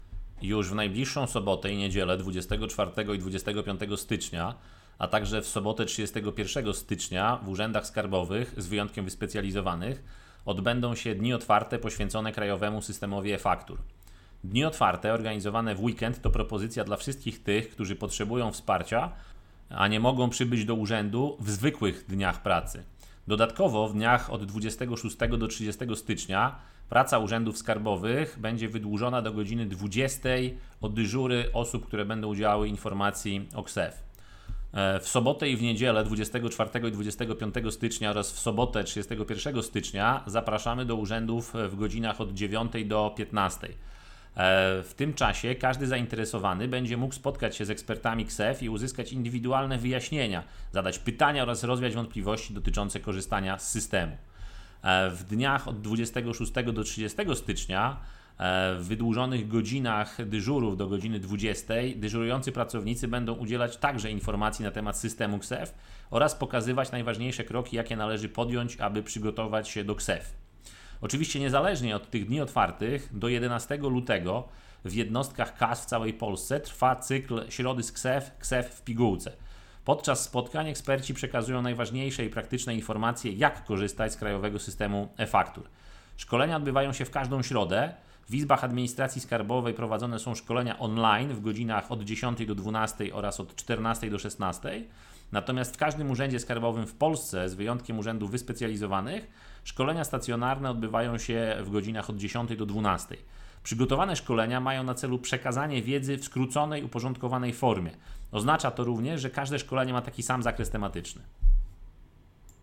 Dni Otwarte KSeF - wypowiedź